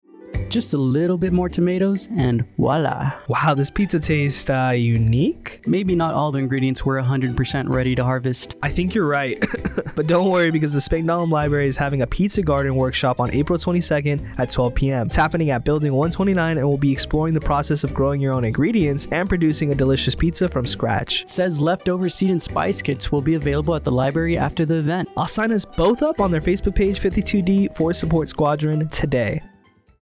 a 30 second radio spot on the pizza garden and spice club class.